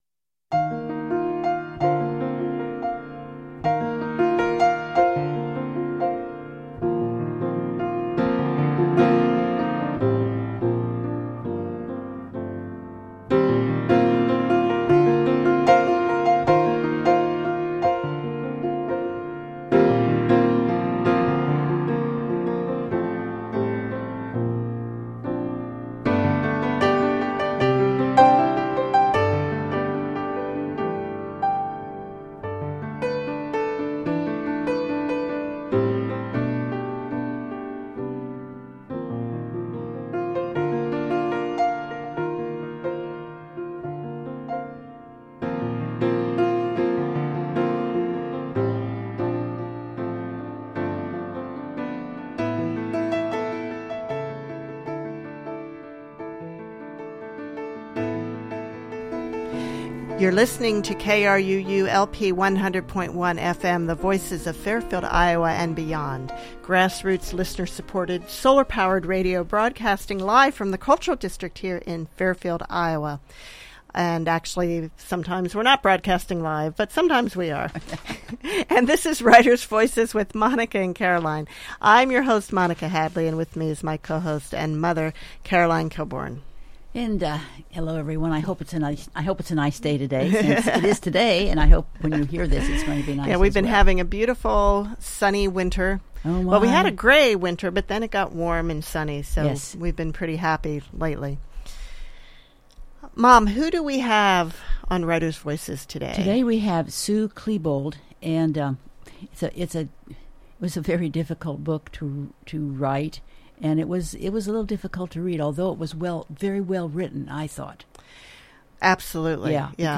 Writers Voices talks with Sue Klebold on her book – A Mother’s Reckoning – Living in the Aftermath of Tragedy, Sue is the mother of Dylan Klebold one of the two shooters at Columbine High school in 1999.